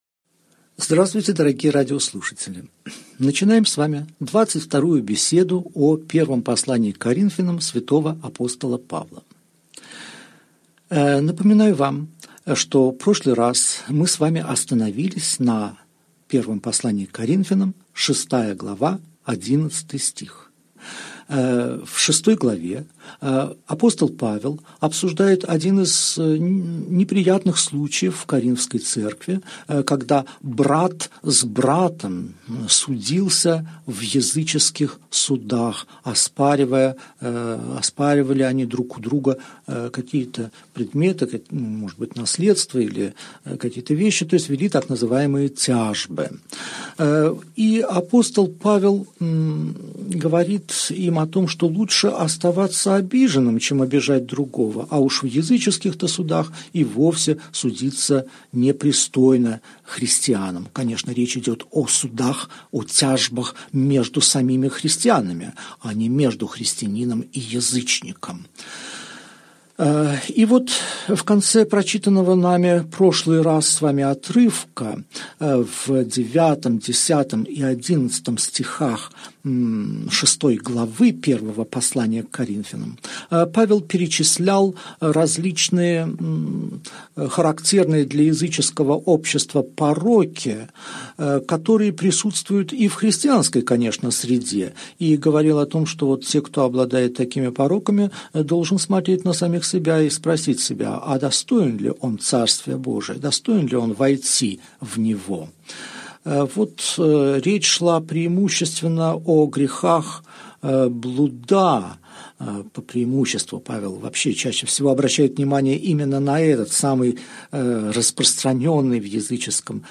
Аудиокнига Беседа 22. Первое послание к Коринфянам. Глава 6, стихи 12 -20 | Библиотека аудиокниг